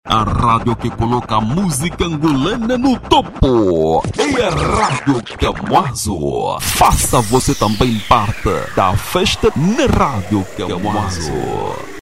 Masculino
Publicidade - Rádio